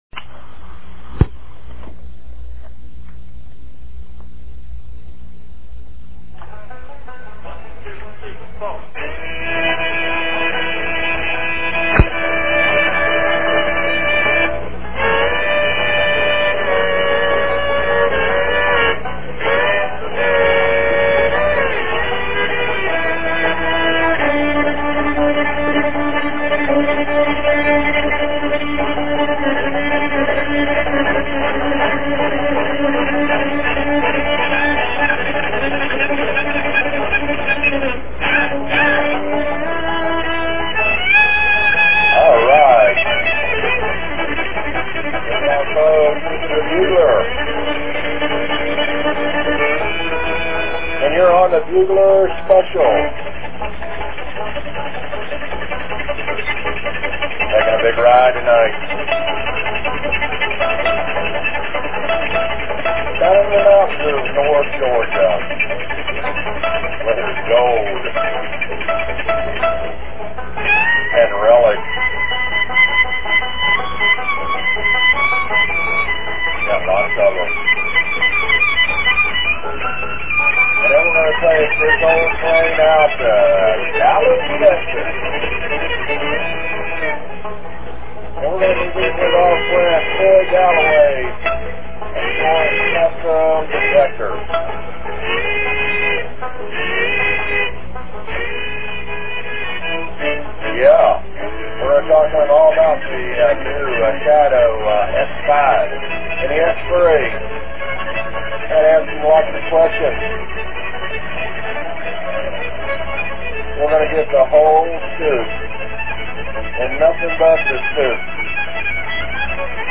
I was so enthralled with his bold venture of designing a metal detector beyond what all the big manufacturers had ever offered, that I even recorded that show.